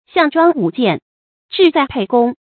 注音：ㄒㄧㄤˋ ㄓㄨㄤ ㄨˇ ㄐㄧㄢˋ ，ㄓㄧˋ ㄗㄞˋ ㄆㄟˋ ㄍㄨㄙ
讀音讀法：